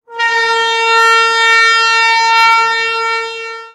基础音效 " S027空气号低沉的声音单声道
描述：从呼吸喇叭发出的声音，低沉而深沉的声音
Tag: 人群 长期爆炸 Airhorn